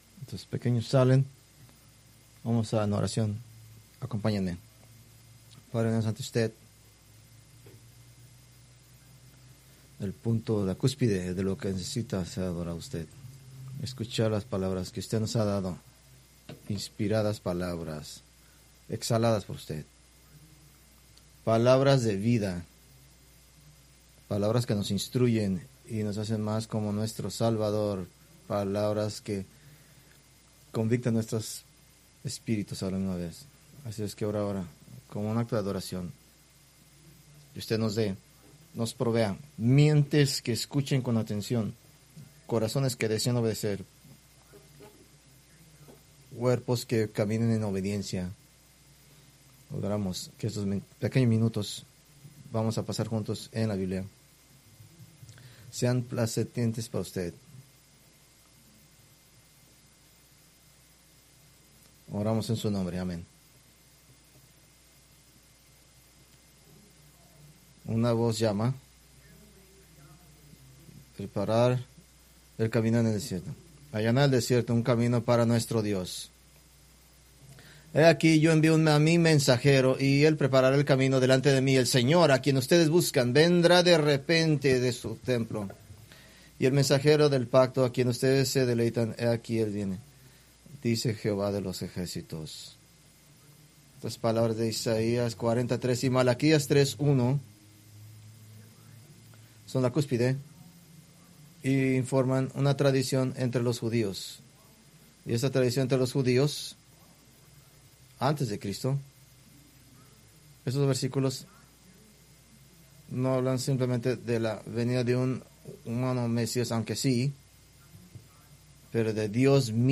Preached October 5, 2025 from Mateo 11:7-15